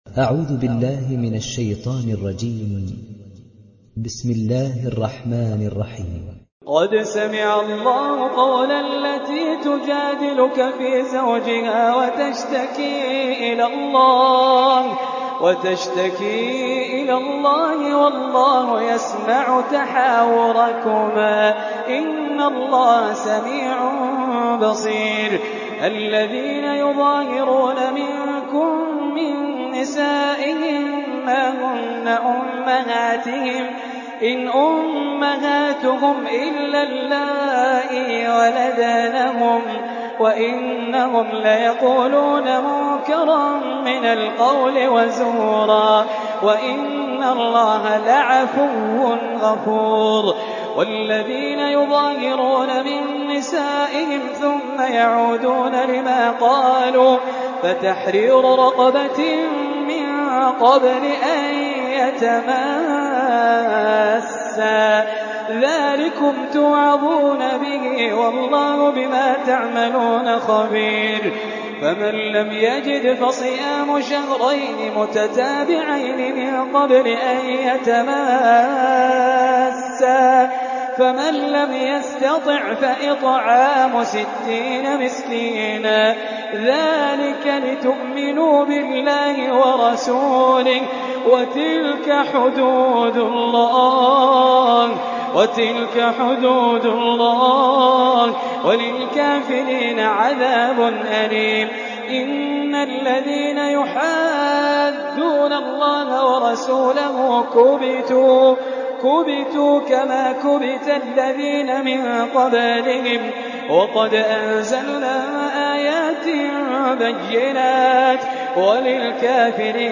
تحميل سورة المجادلة mp3 بصوت خالد الجليل برواية حفص عن عاصم, تحميل استماع القرآن الكريم على الجوال mp3 كاملا بروابط مباشرة وسريعة